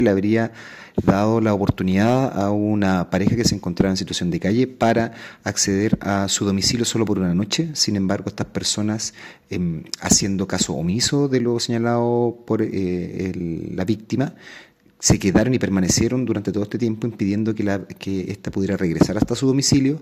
El fiscal jefe de Traiguén, Felipe González, sostuvo que los imputados fueron acogidos por el dueño de la casa, un adulto mayor, que les permitió pasar una noche en el lugar, sin embargo, terminaron apropiándose del inmueble.